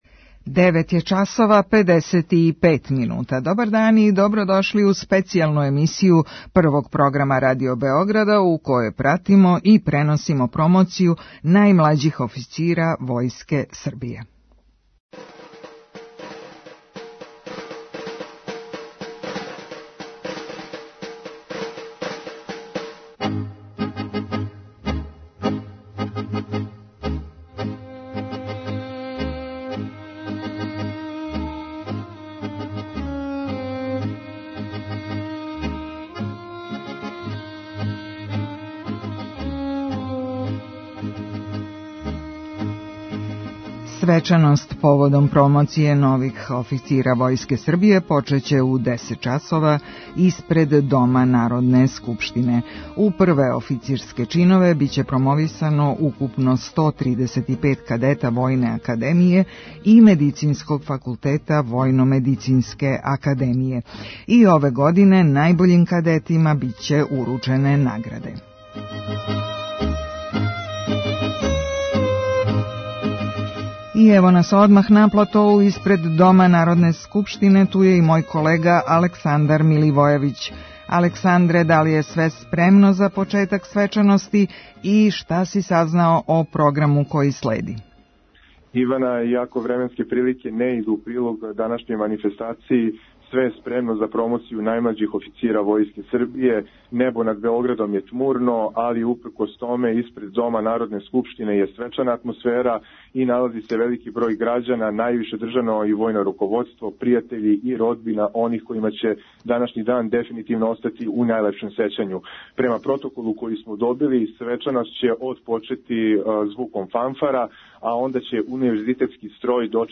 Program je organizovan ispred Doma narodne skupštine uz učešće avijacije Vojske Srbije, kao i bogat umetnički program koji će realizovati umetnički ansambl Ministarstva odbrane „Stanislav Binički“ i Reprezentativni orkestar Garde.
Specijalna emisija
Reporteri Prvog programa Radio Beograda su na licu mesta